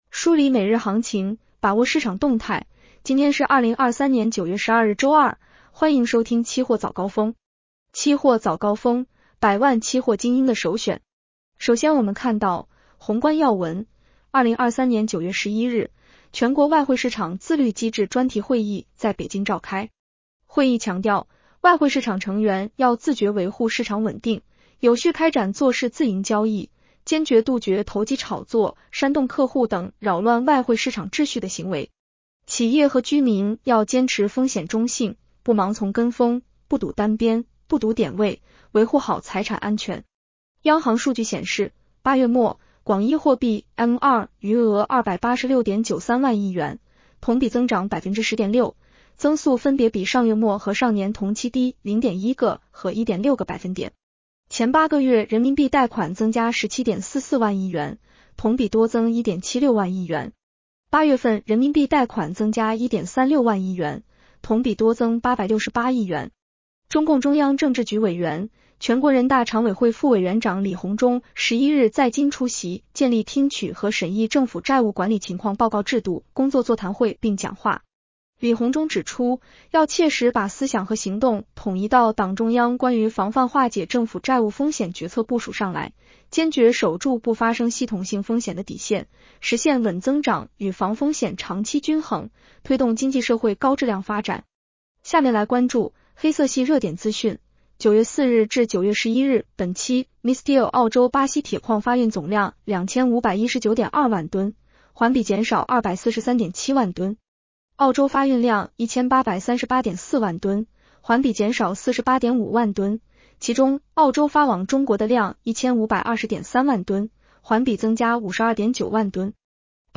【期货早高峰-音频版】 女声普通话版 下载mp3 宏观要闻 1. 2023年9月11日，全国外汇市场自律机制专题会议在北京召开。